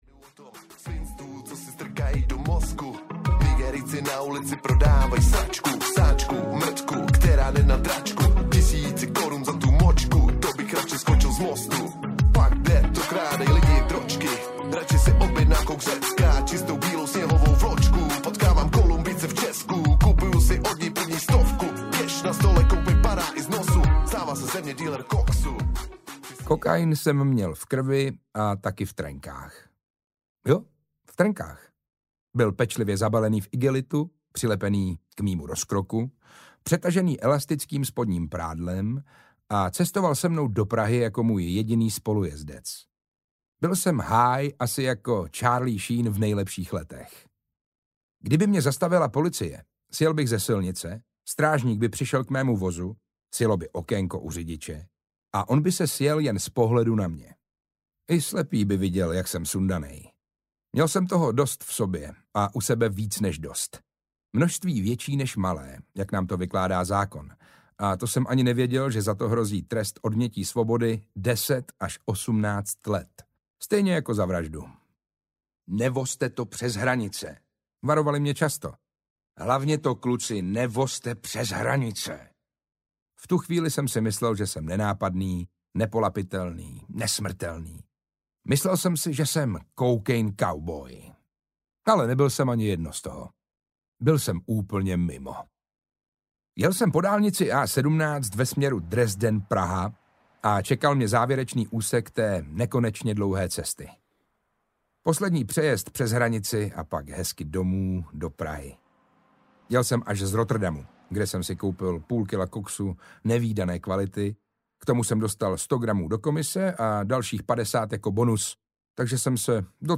Ukázka z knihy
muklove-a-slajsny-audiokniha